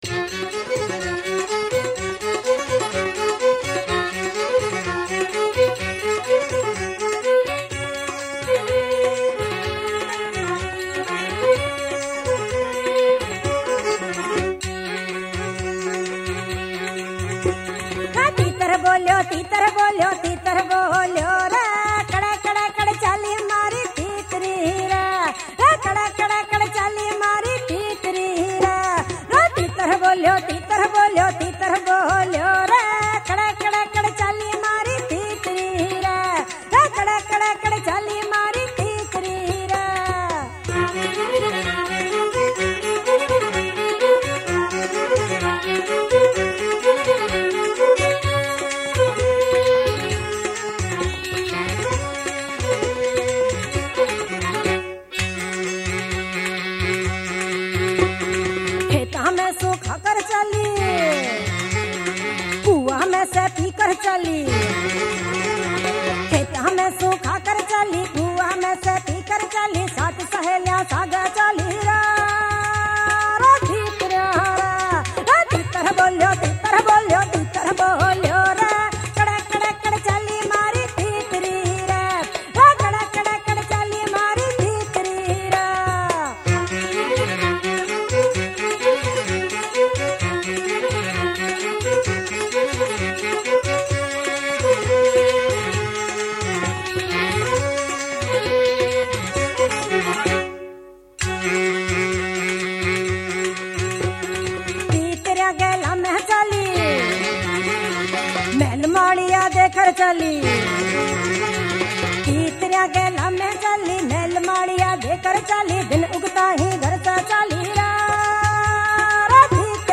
Rajasthani Folk Songs